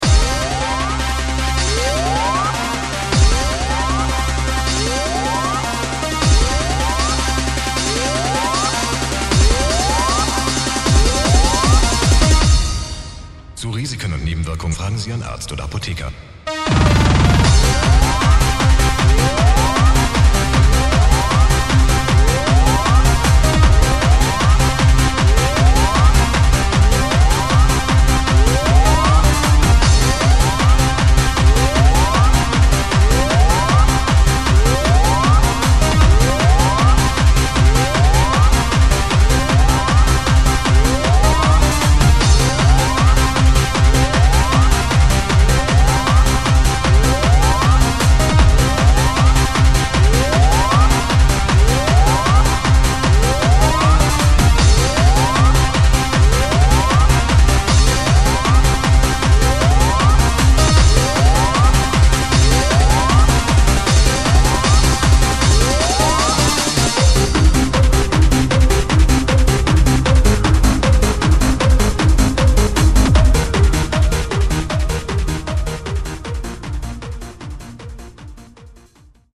Hard-Trance, Progressive House